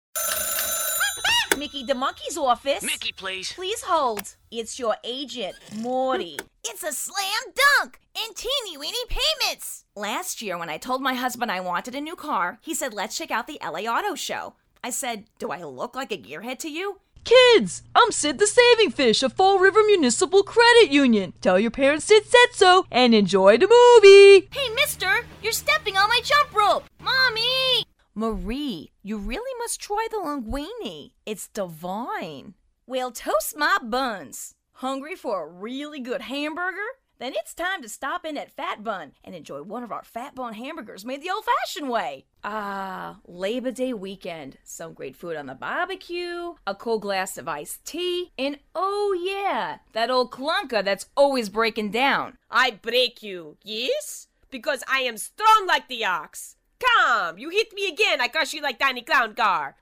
Demo
Teenager, Adult, Young Adult
Has Own Studio
mid atlantic
southern us
standard us
friendly
smooth
warm